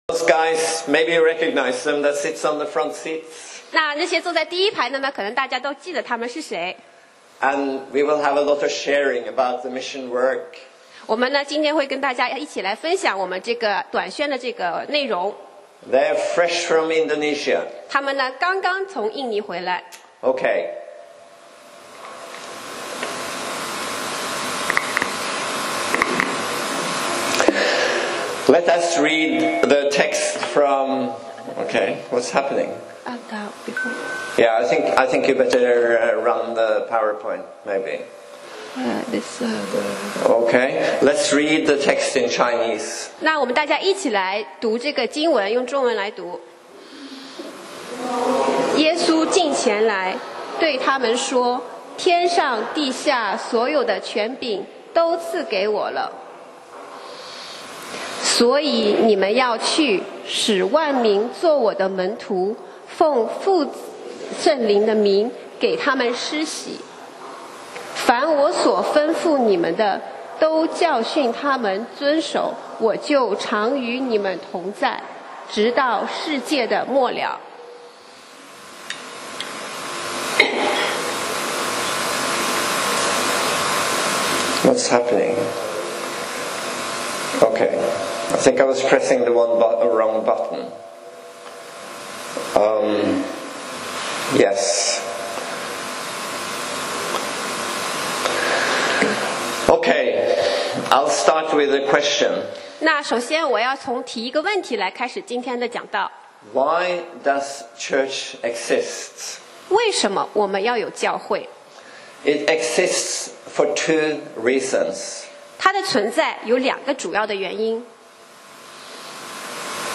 講道 Sermon 題目 Topic：宣召使命 Call to mission 經文 Verses：馬太福音 28:18-20. 18耶稣进前来，对他们说，天上，地下所有的权柄，都赐给我了。